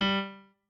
pianoadrib1_9.ogg